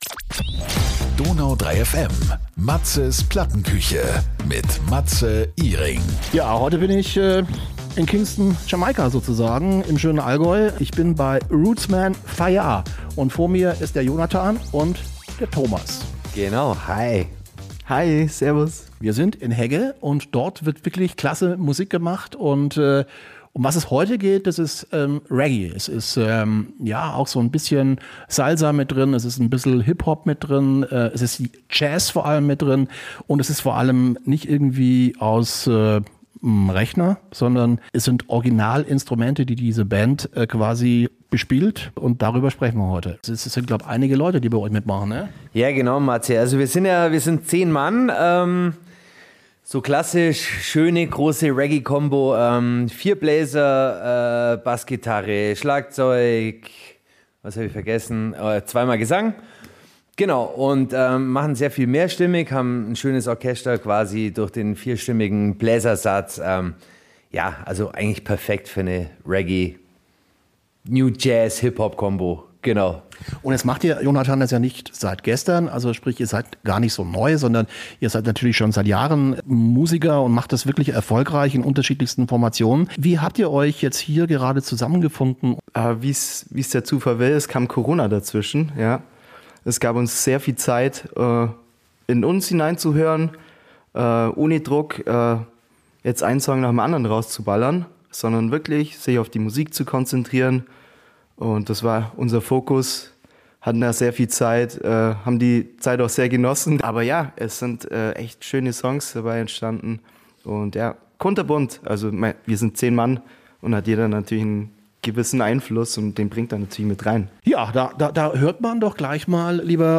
Ein spannendes Gespräch mit einer spannenden Band - in der Plattenküche.